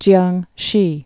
(jyängshē) also Kiang·si (kyäng-)